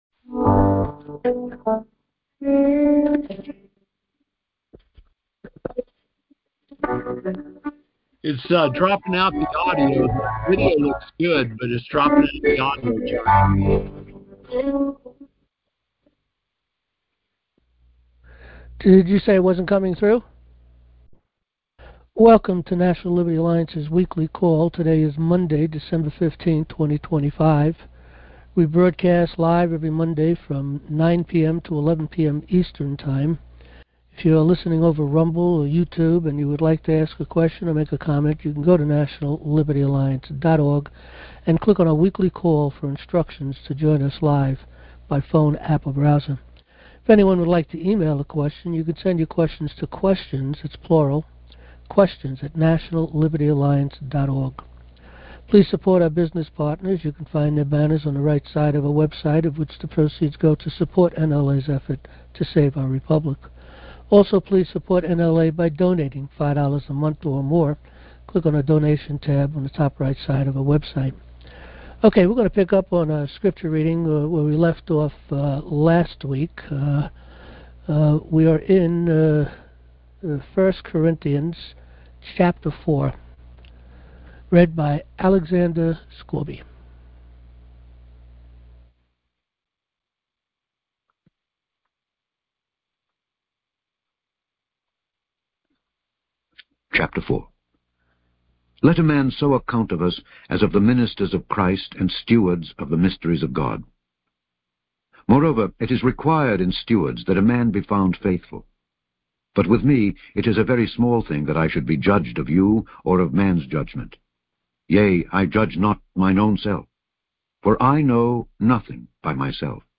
Join our Live Monday Night Open Forum | National Liberty Alliance
Monday Night Recordings